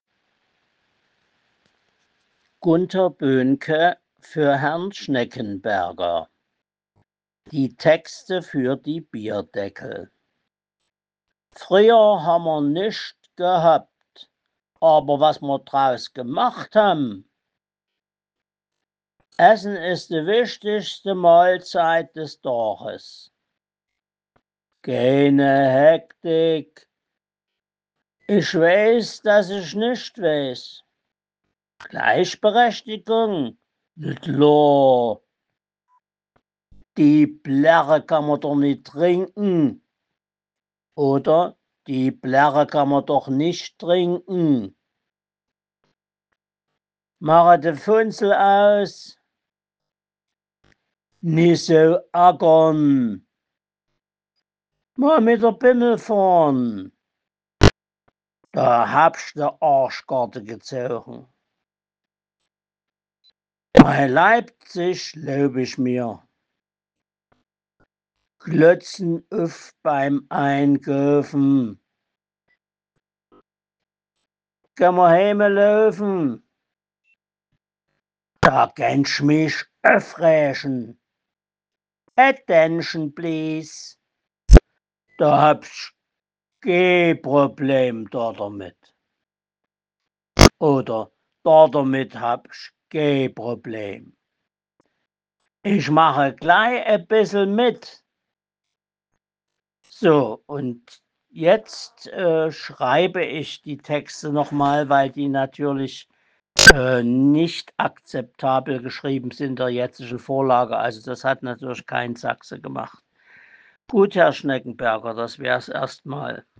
Die SDGs auf Sächsisch